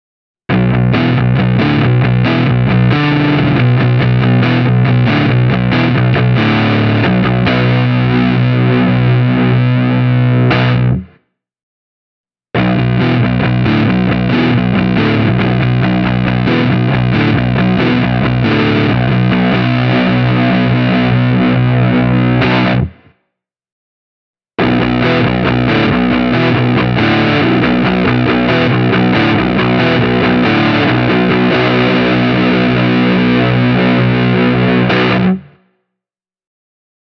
And the moderately-powered bridge humbucker adds just the right amount of bite and grit to proceedings.
Listen to these two audio clips (the pickup order is: neck – both – bridge):
Hagström Viking Deluxe Baritone – crunchy